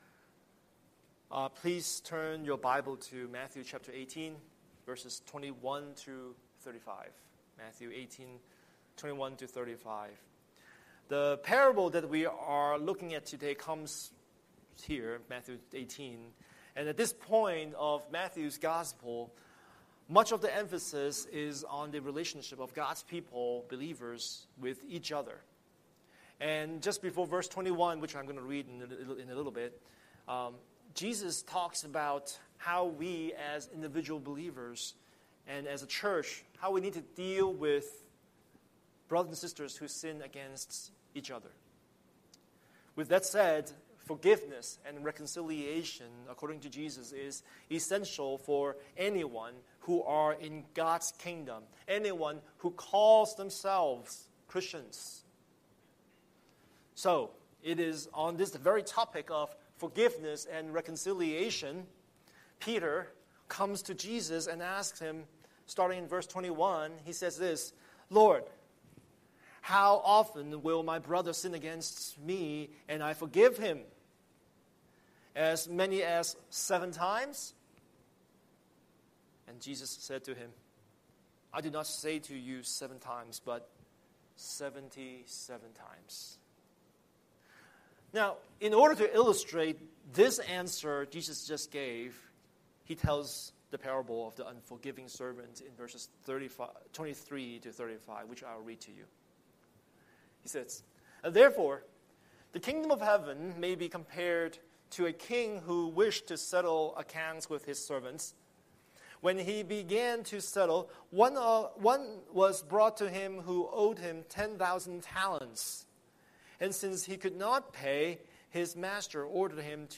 Scripture: Matthew 18:21–35 Series: Sunday Sermon